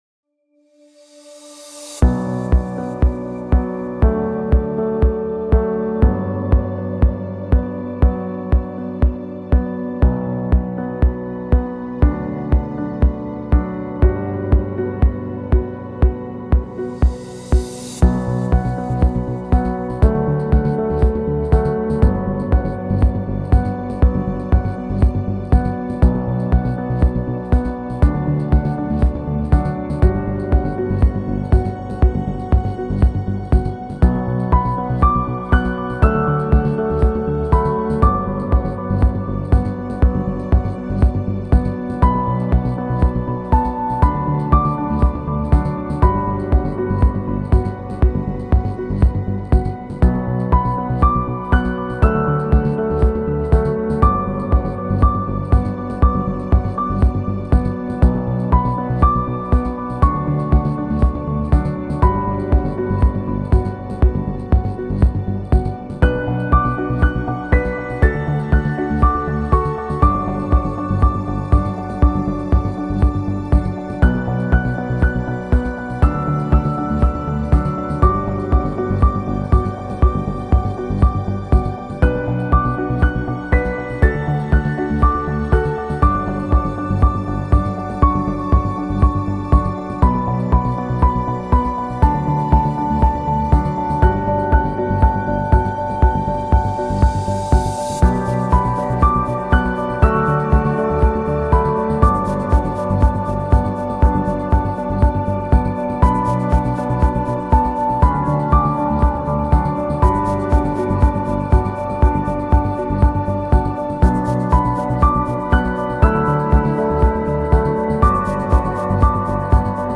KFtuiaZD2Nf_musica-cinematografica-moderna-musica-instrumental.mp3